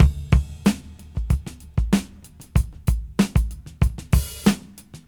• 94 Bpm Drum Beat D Key.wav
Free breakbeat sample - kick tuned to the D note. Loudest frequency: 905Hz
94-bpm-drum-beat-d-key-GMU.wav